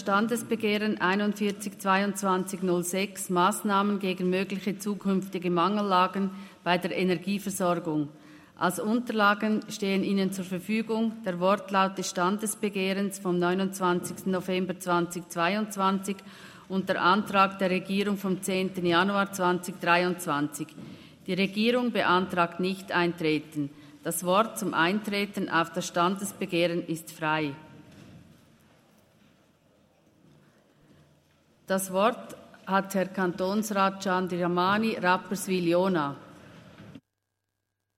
Session des Kantonsrates vom 18. bis 20. September 2023, Herbstsession
18.9.2023Wortmeldung